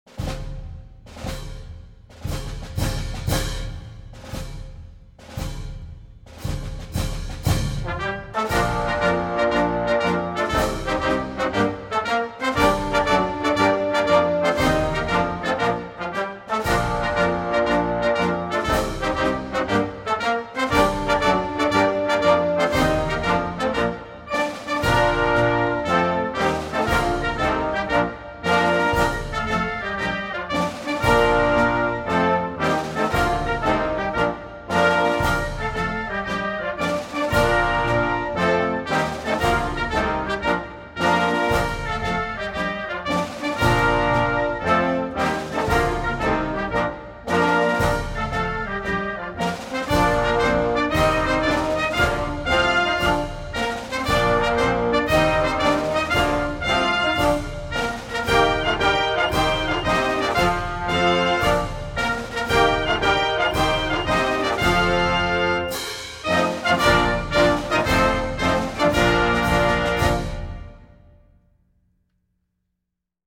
Kassaman_instrumental.mp3